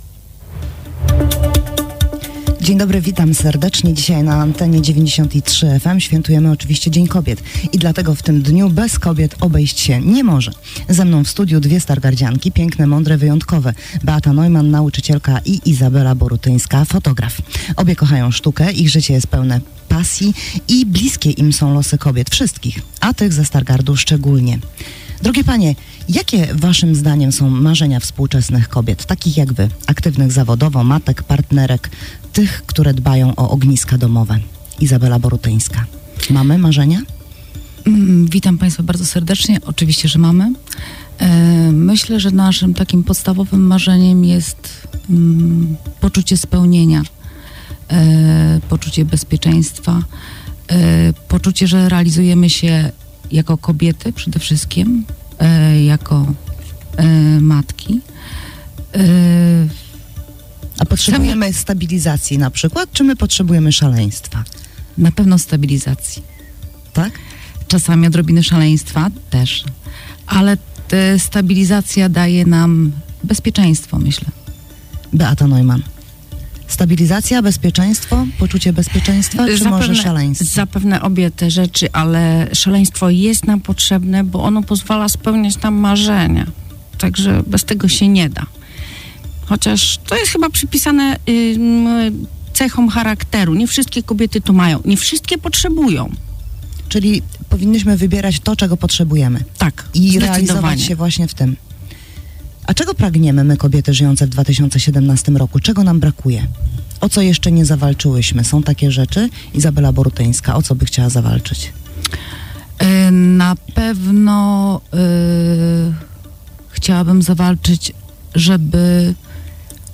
Dzień Kobiet to również dla wielu okazja do dyskusji – czego pragną i potrzebują dzisiejsze kobiety. Na ten temat dziś dyskutowaliśmy na 90.3 FM.
Posłuchaj całej rozmowy z Gośćmi Dnia